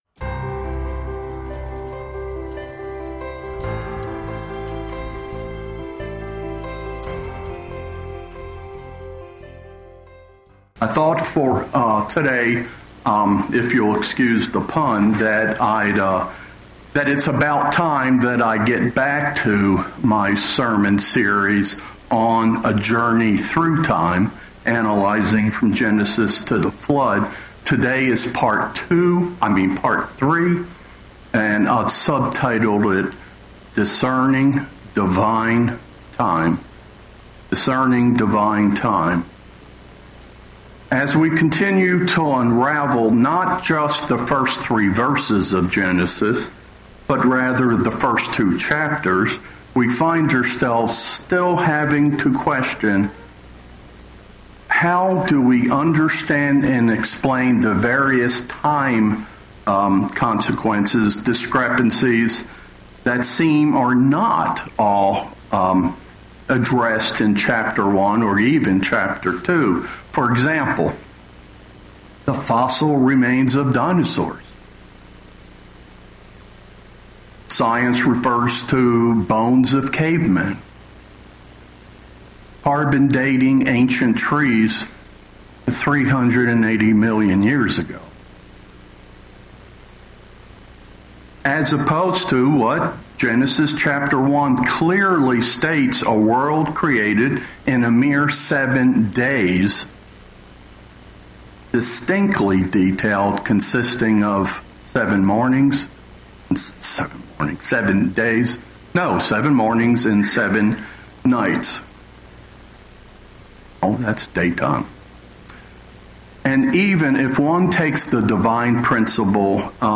In Part 3 of this extensive sermon series, we will continue to explore the very detailed, and orderly process that God designed when He created the world.